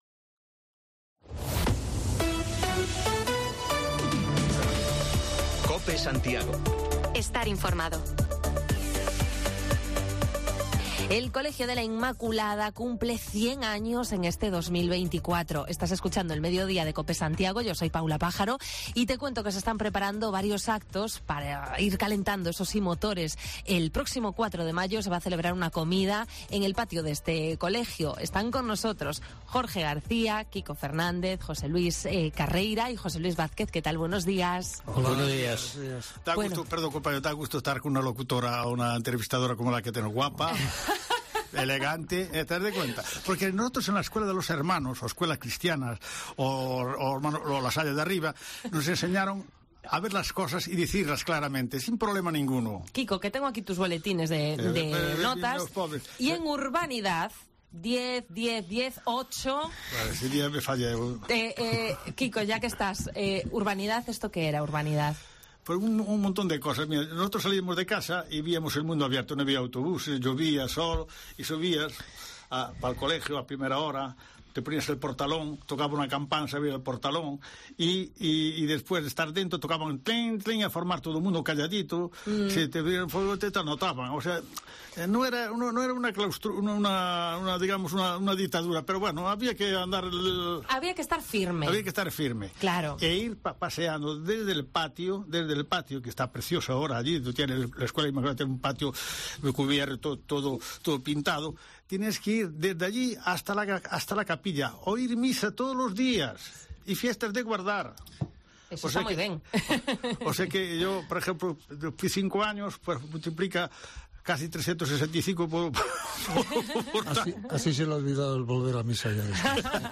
El Colegio de la Inmaculada cumple 100 años este 2024: el próximo 4 de mayo habrá comida entre antiguos alumnos para celebrarlo. Varios ex estudiantes se acercaron hoy a Cope Santiago y nos contaron recuerdos de su vida escolar